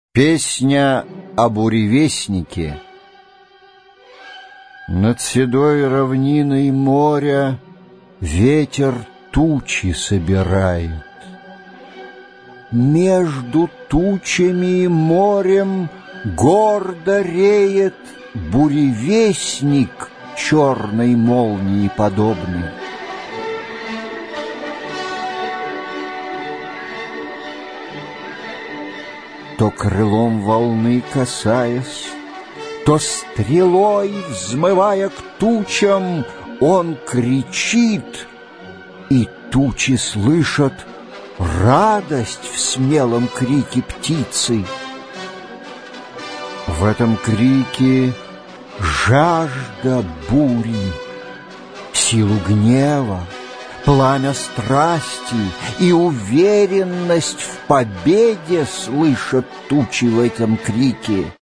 Аудиокнига Избранное | Библиотека аудиокниг